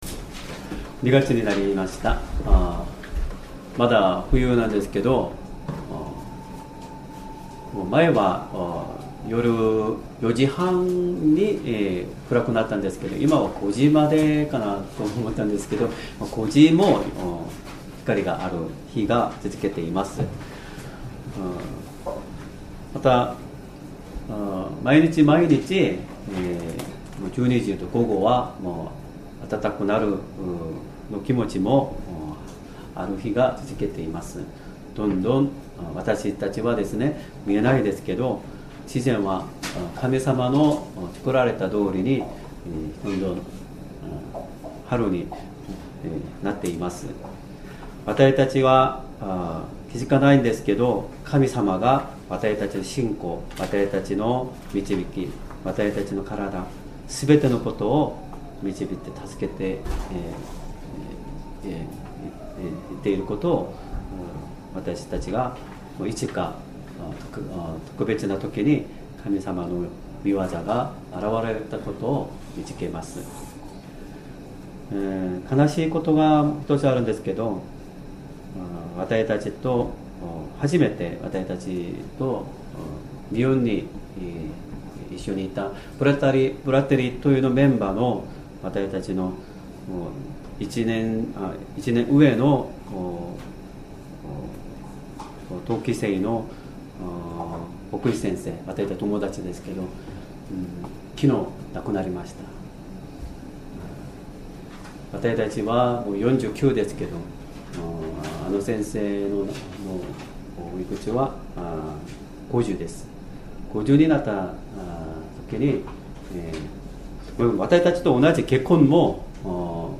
Sermon
2026年2月1日 主日礼拝 説教 「イエスの名によって求める祈り」 聖書 ヨハネの福音書 16章 25-26節 16:25 わたしはこれらのことを、あなたがたにたとえで話しました。